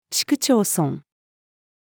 市区町村-female.mp3